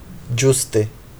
Ĝuste [ˈdʒus.te]